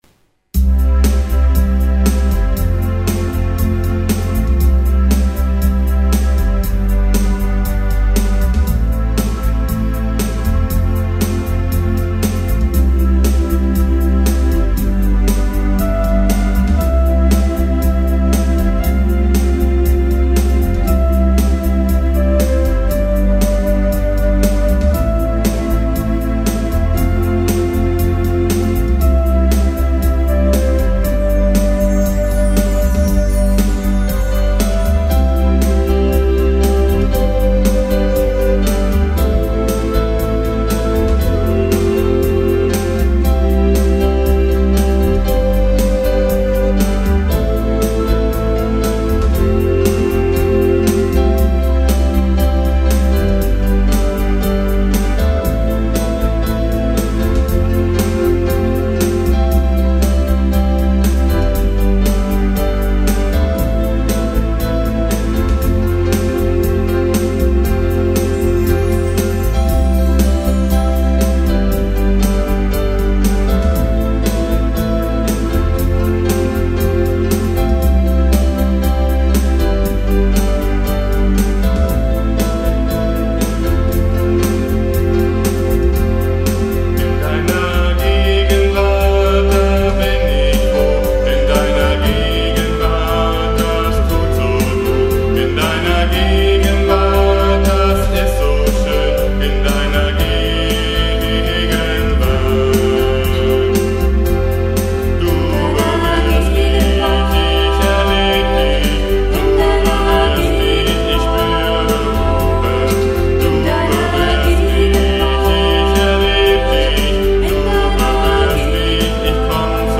Gitarre, Flöte, Gesang
Tasten, Cajon, Chimes, Gesang